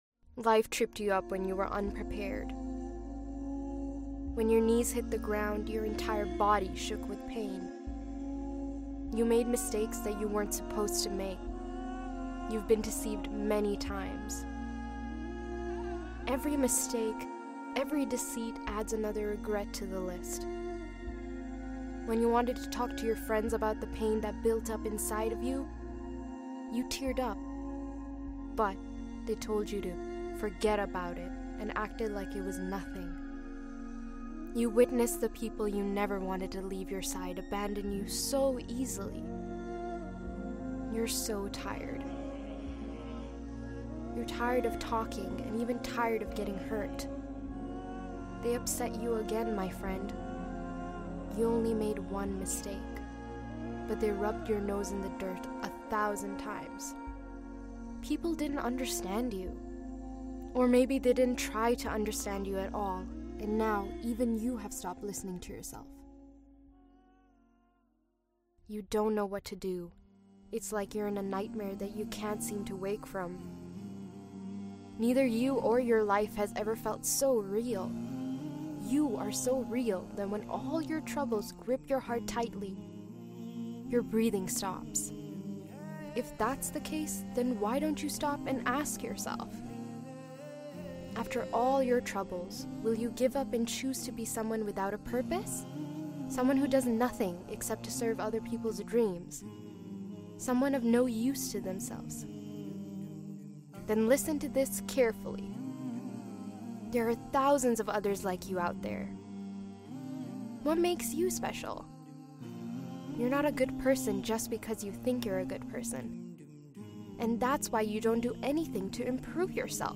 lectures
Allah Knows That You're Suffering ⧸ Powerful Motivational Speech.mp3